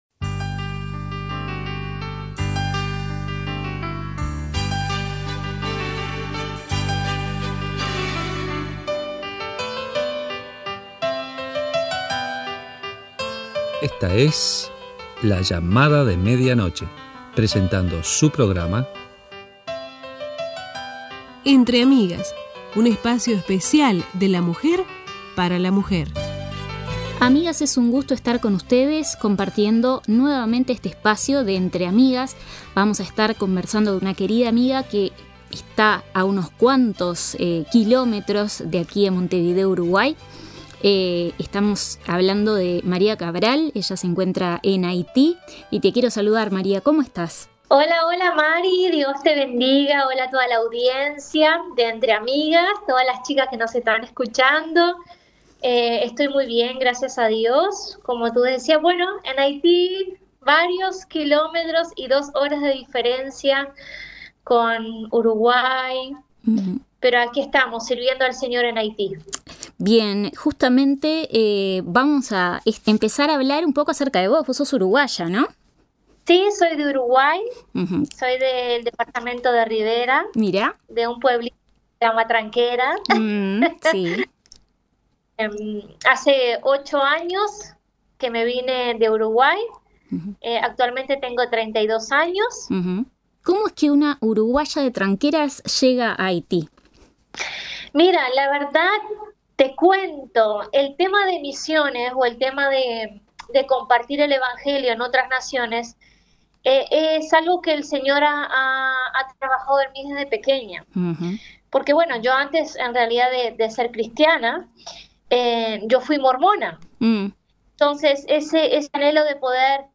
Te invitamos a acompañarnos en esta primera parte de la entrevista.